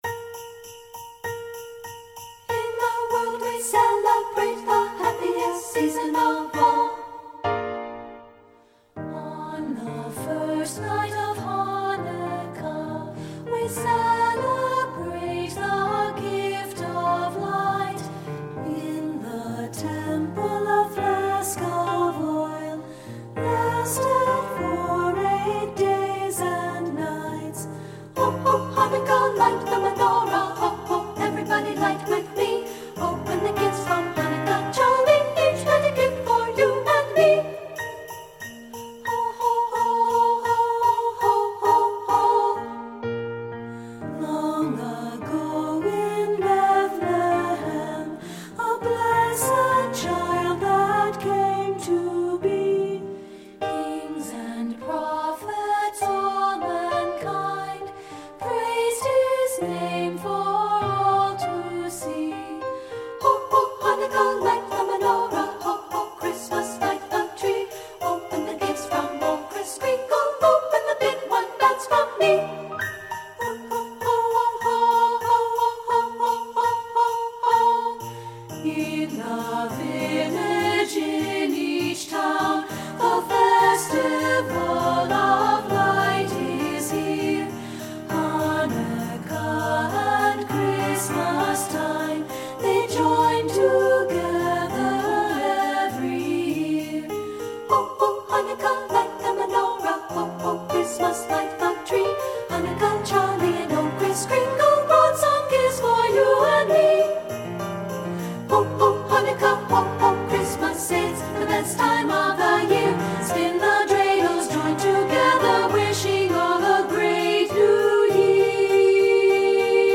Studio Recording
Ensemble: Unison and Two-Part Chorus
Key: E major
Accompanied: Accompanied Chorus